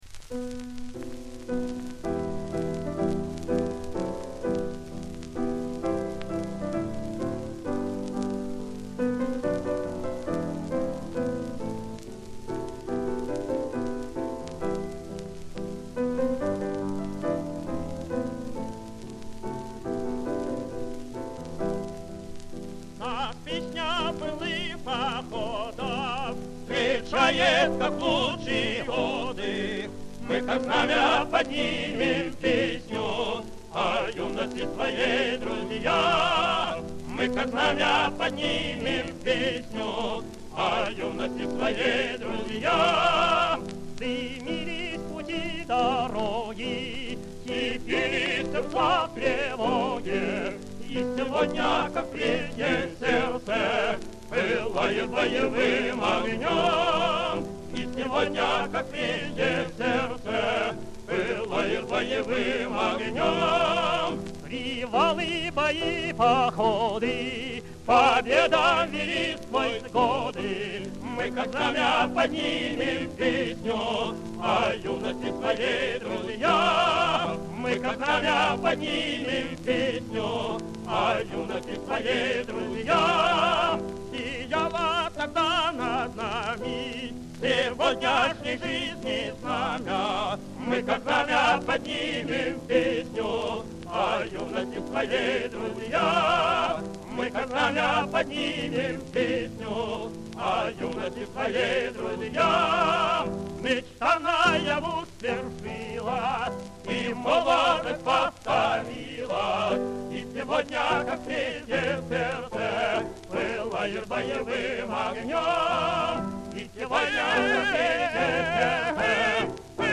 Самый ранний вариант исполнения из имеющихся на сайте.
Вокальный квартет
Ф-но